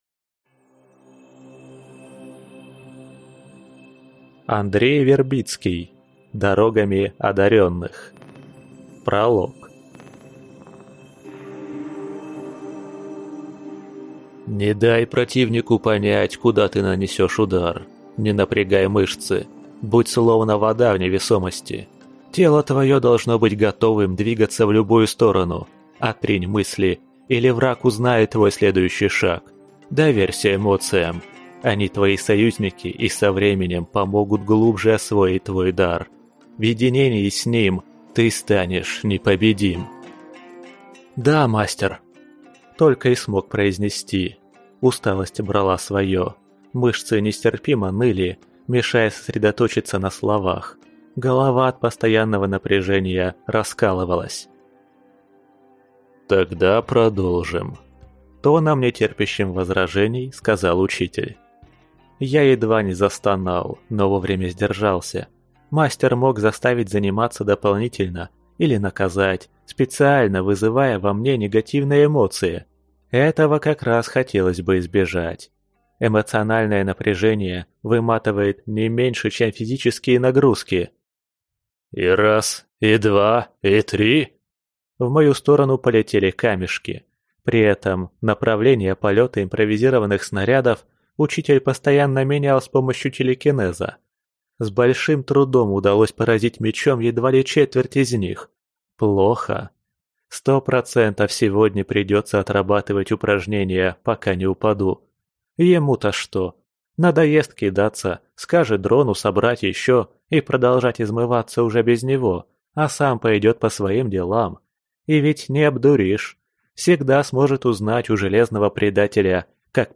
Аудиокнига Дорогами Одарённых | Библиотека аудиокниг